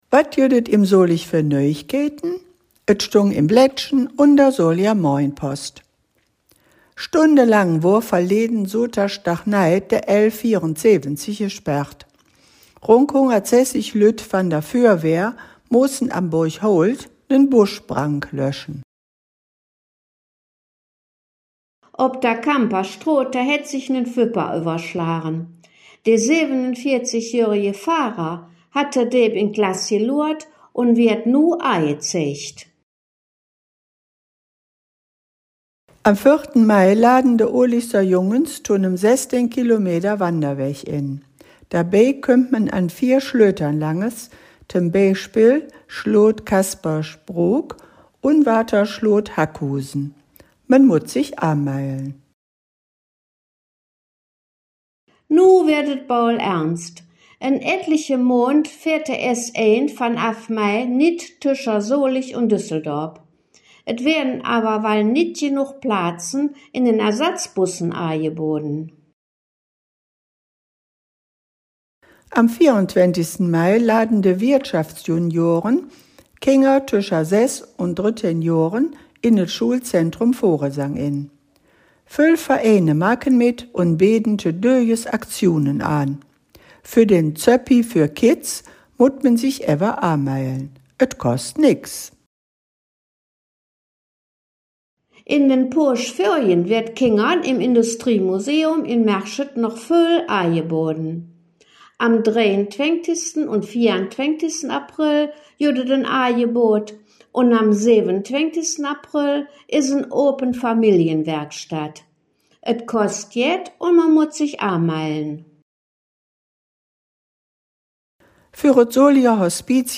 In dieser Folge "Dös Weeke em Solig" blicken de Hangkgeschmedden in Solinger Platt auf die Nachrichten vom 11. April 2025 bis zum 17. April 2025 zurück.